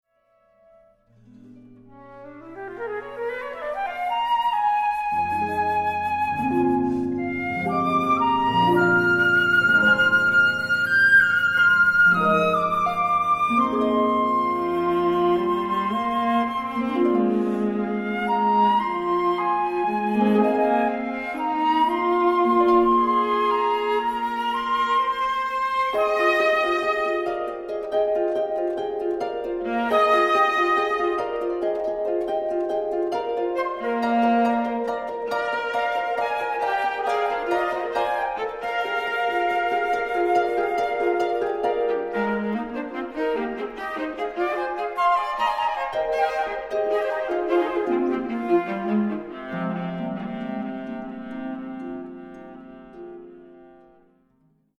Fantasy for Flute, Viola and Harp